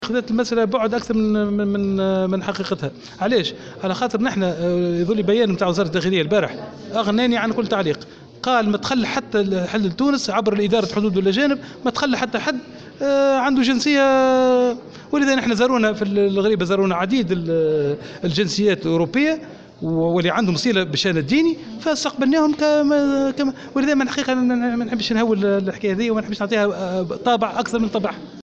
وأوضح في تصريح لمراسلة الجوهرة اف ام، أن عديد الزوار من جنسيات أروبية مختلفة زاروا معبد الغريبة، لكن وزارة الداخلية أكدت عدم حلول أي صحفي حامل لجواز سفر إسرائيلي أو ممثل لأية وسيلة إعلام إسرائيلية خلال الاحتفالات السنوية لزيارة الغريبة.